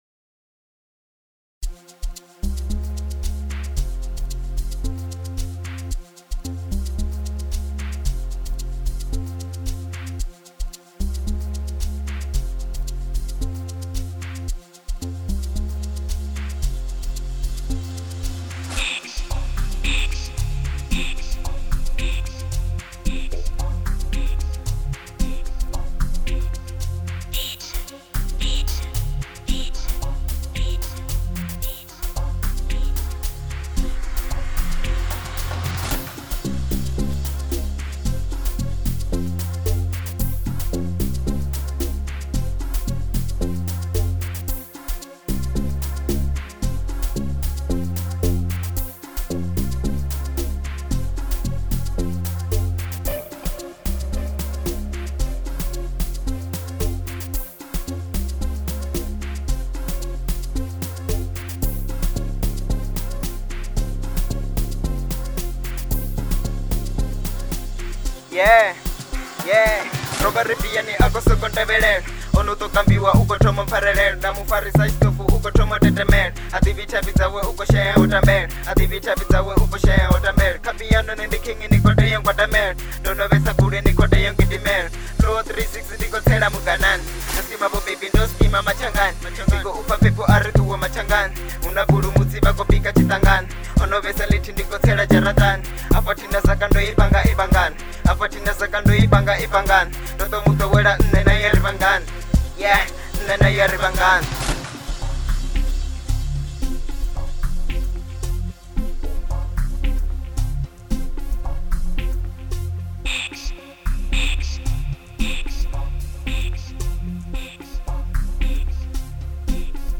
03:54 Genre : Amapiano Size